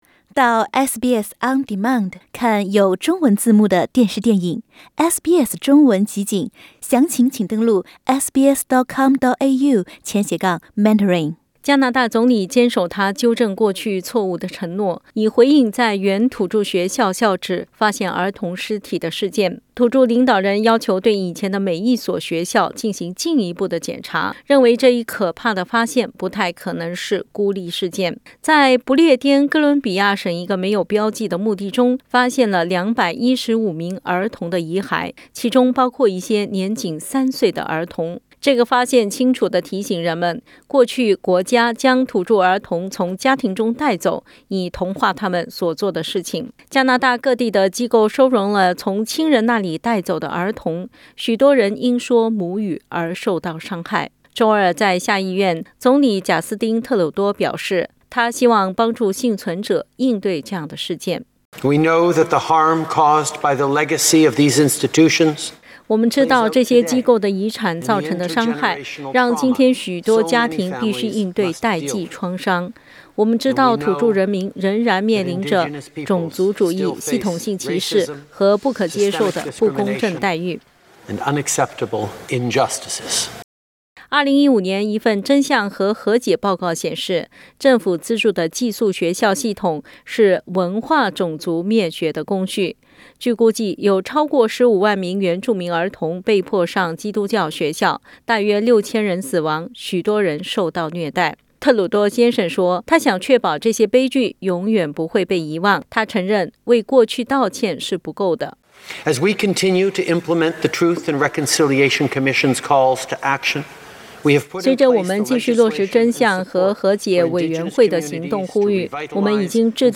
(请听报道） 澳大利亚人必须与他人保持至少1.5米的社交距离，请查看您所在州或领地的最新社交限制措施。